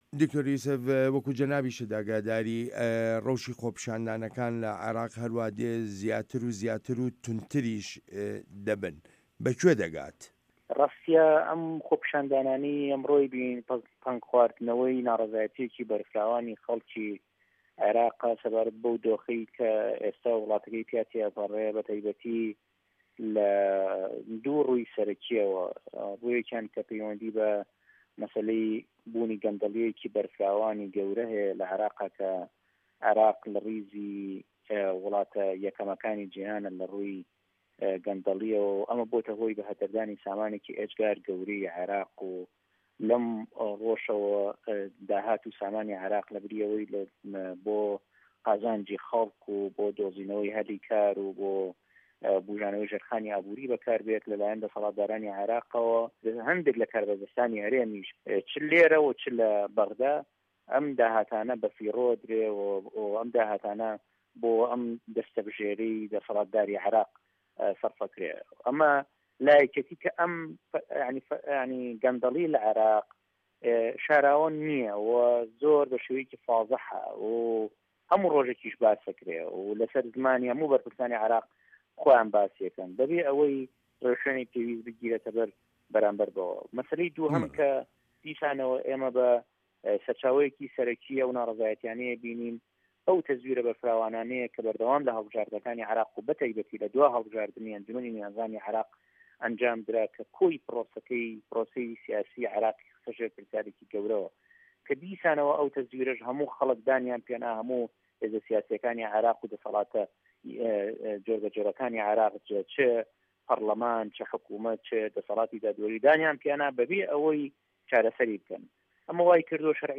وتووێژ لەگەڵ دکتۆر یوسف موحەمەد